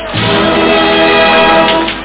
1 channel
tusch.mp3